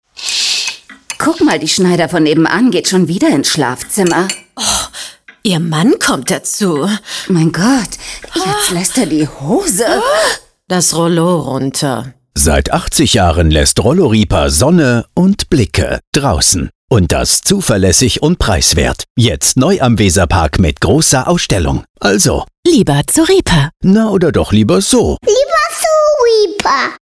Während der eine Spot Informationen über die Winterpreise, das 80-jährige Bestehen des Unternehmens und den neuen Standort in der Nähe vom Weserpark mit einem sehr sympathischen und ansteckenden Lachen kombiniert, erzählt der andere Spot eine Geschichte zweier Frauen, die ganz gespannt ihre Nachbarn beobachten.